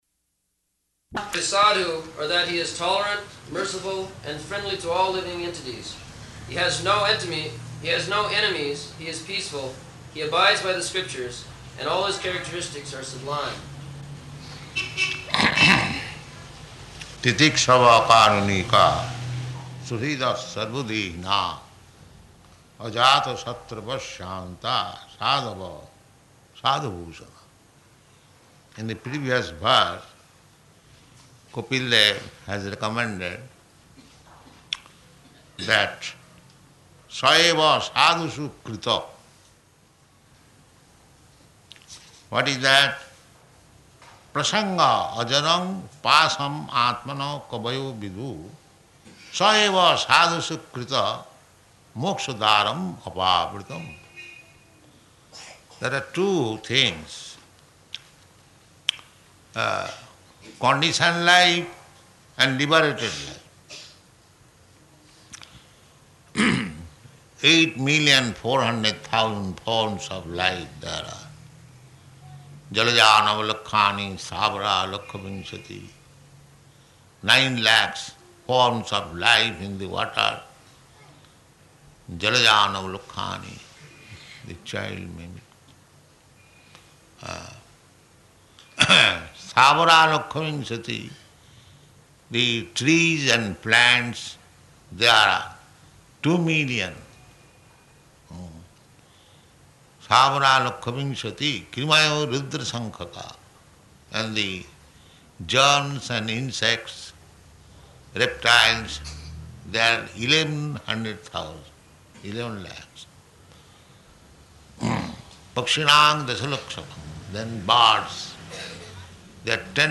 Type: Srimad-Bhagavatam
Location: Bombay